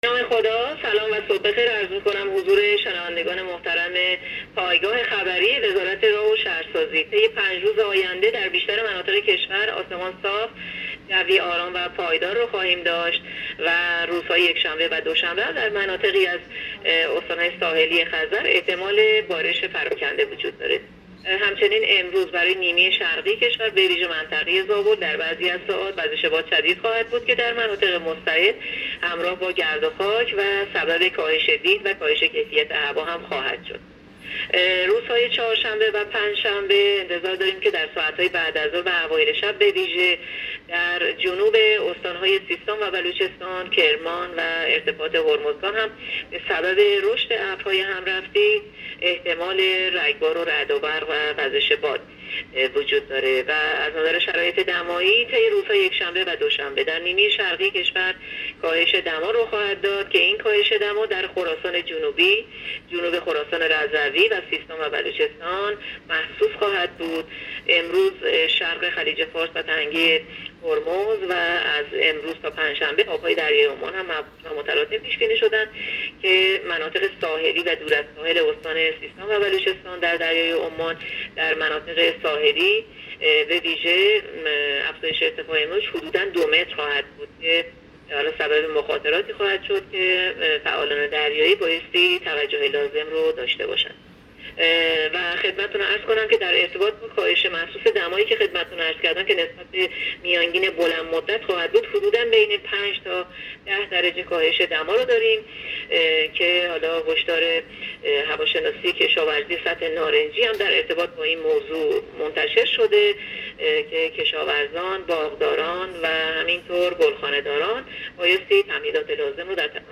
گزارش رادیو اینترنتی پایگاه‌ خبری از آخرین وضعیت آب‌وهوای سیزدهم مهر؛